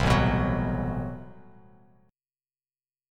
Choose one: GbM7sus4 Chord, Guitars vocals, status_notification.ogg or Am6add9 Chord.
Am6add9 Chord